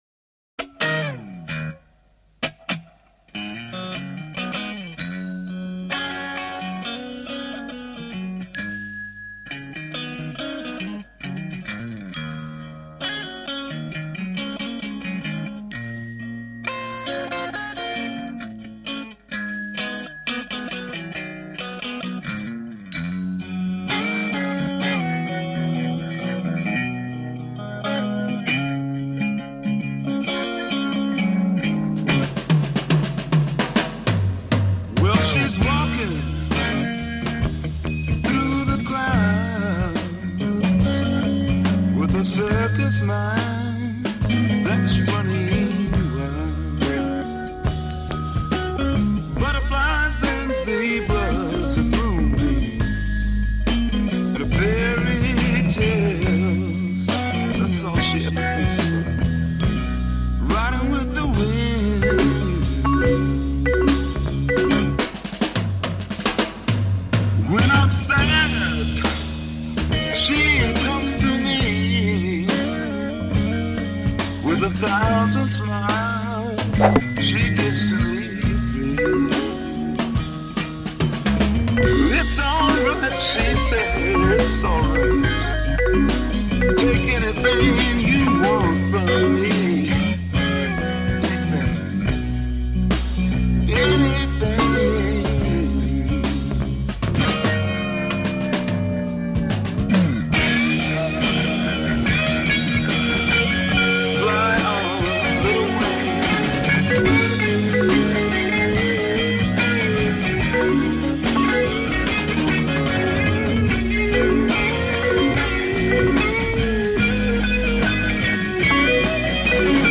rock band
chitarristico chord/melody